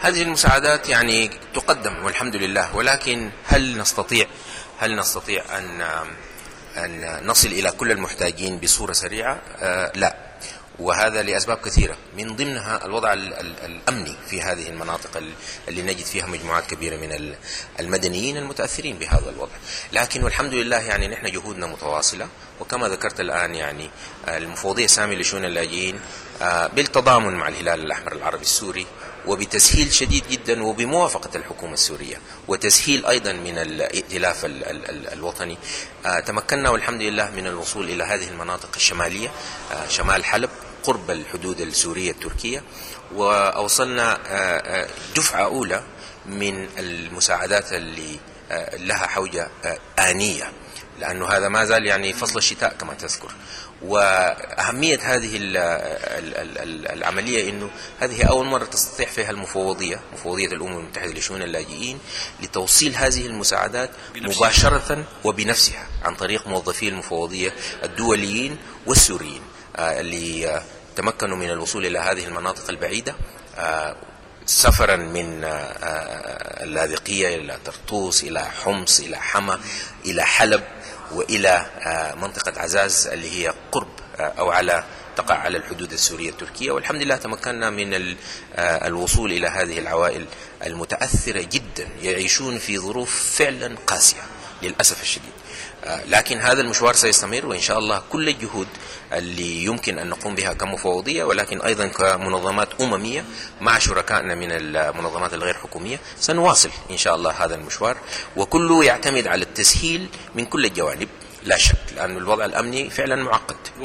حوار خاص